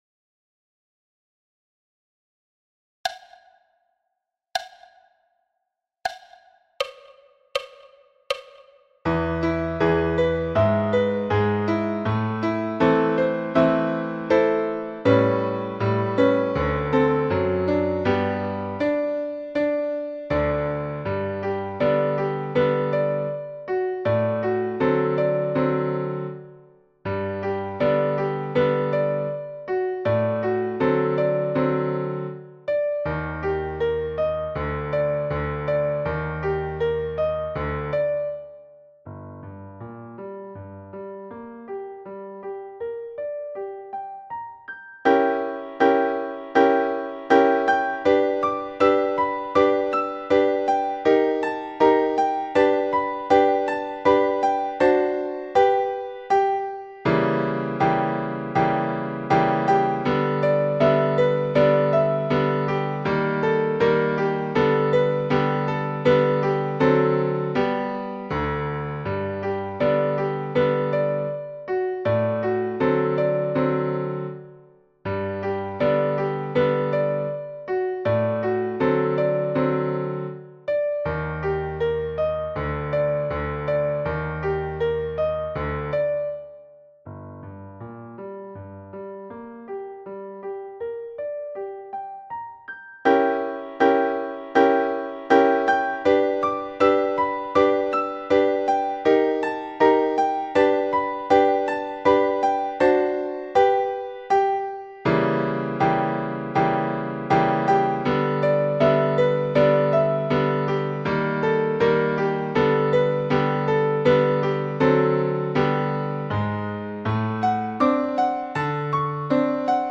Mapple leaf rag – piano à 80 bpm
Mapple-leaf-rag-piano-a-80-bpm.mp3